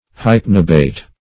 Hypnobate \Hyp"no*bate\, n.
hypnobate.mp3